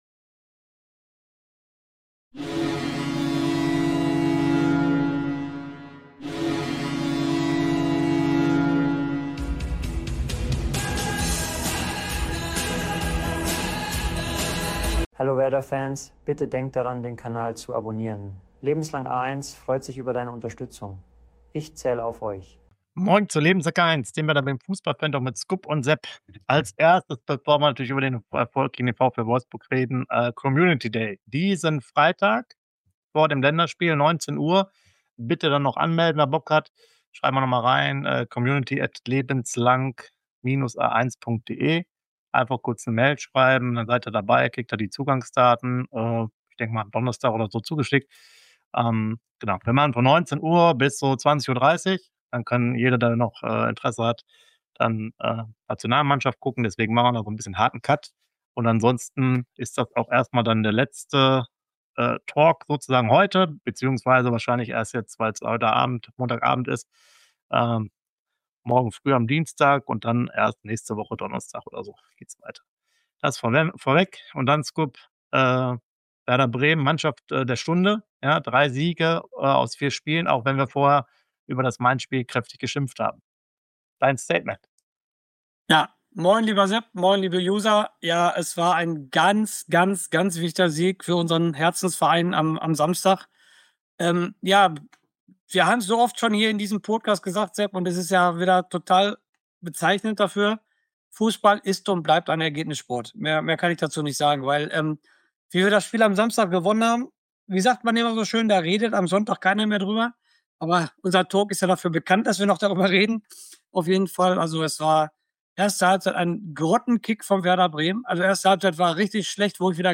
WERDER BREMEN FUßBALL FANTALK!!! Hier gibt es Infos, News und heiße Diskussionen vor, zwischen und nach den Spieltagen zu unserem Verein.